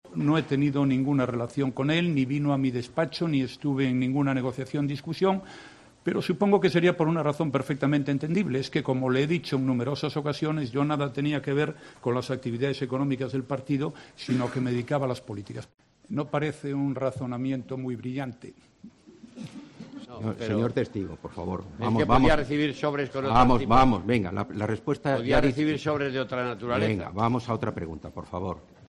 El presidente del Gobierno ha declarado en la Audiencia Nacional como testigo en el juicio de la trama Gürtel por los sobresueldos y los papeles de Bárcenas